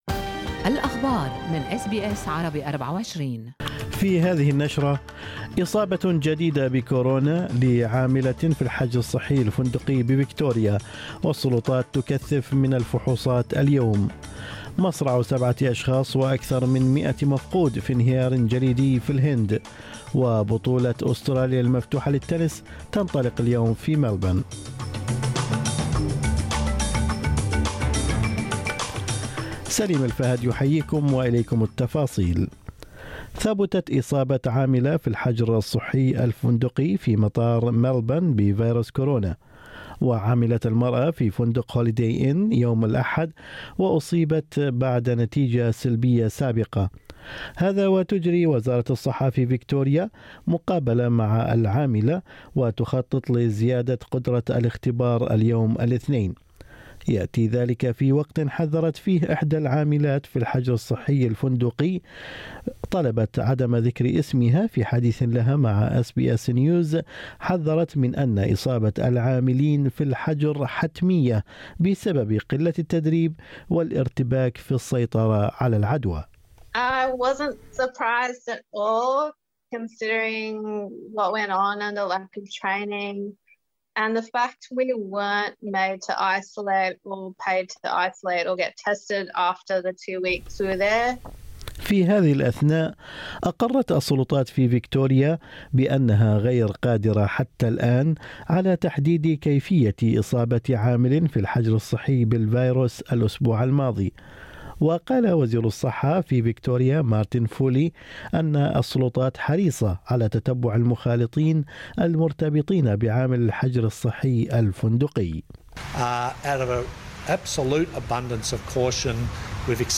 نشرة أخبار الصباح 8/2/2021